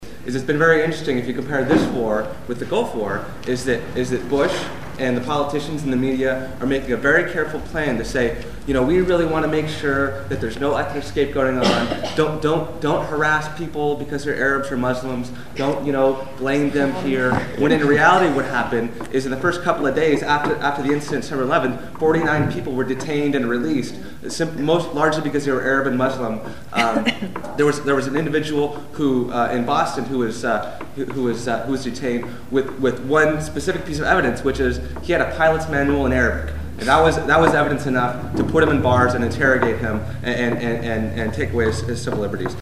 speech at the antiwar organizing meeting in Oakland